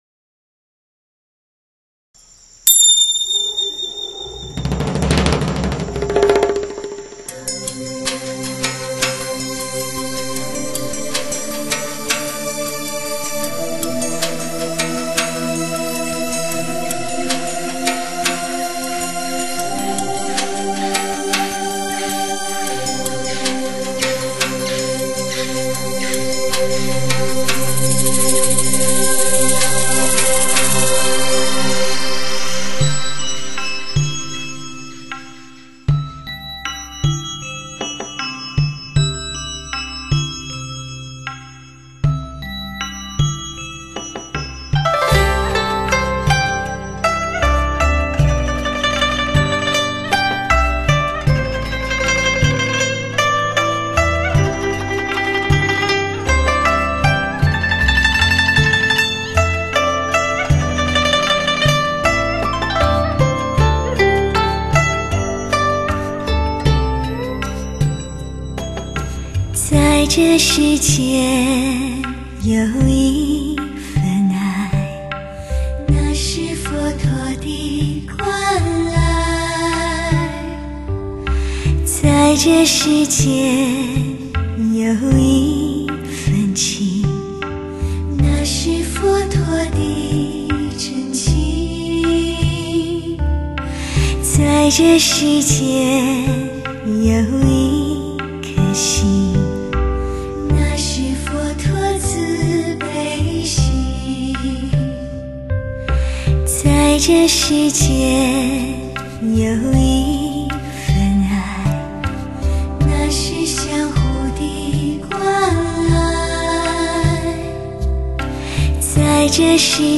6.1声道的音源，创造出澎湃生动的音域环绕效果，满足发烧友最挑剔的要求。